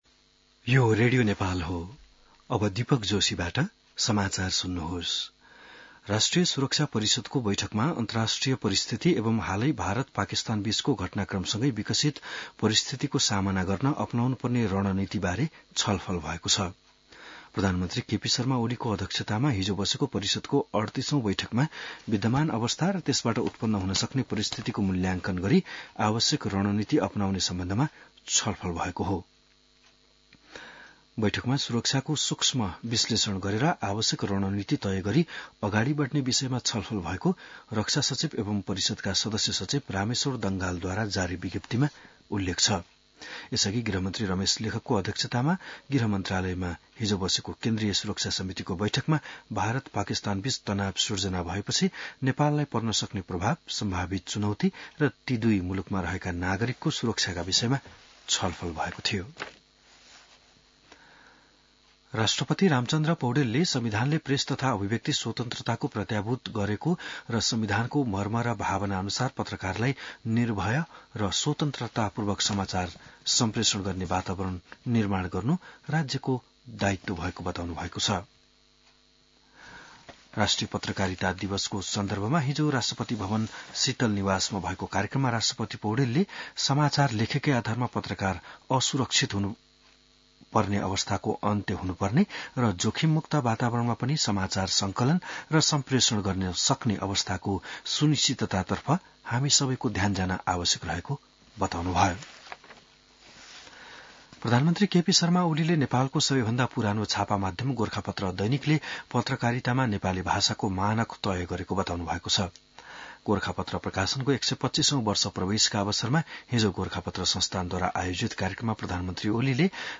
बिहान १० बजेको नेपाली समाचार : २५ वैशाख , २०८२